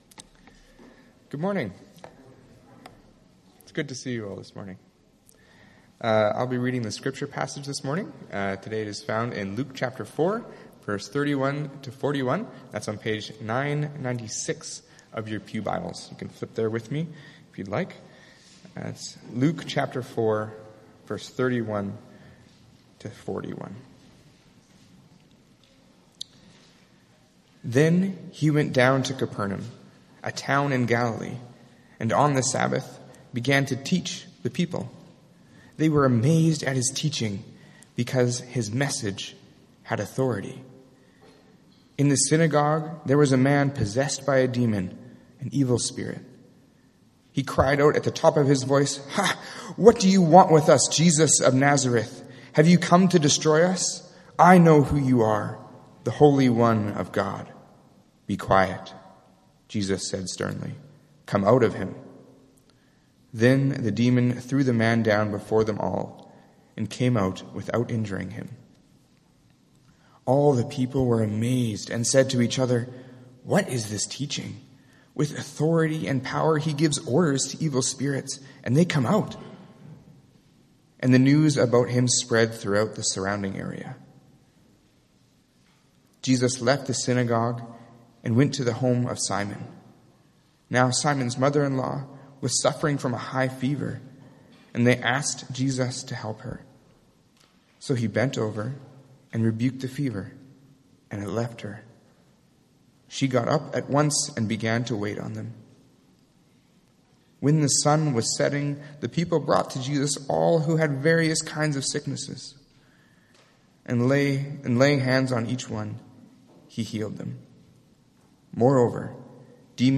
MP3 File Size: 18.8 MB Listen to Sermon: Download/Play Sermon MP3